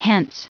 Prononciation du mot hence en anglais (fichier audio)
Prononciation du mot : hence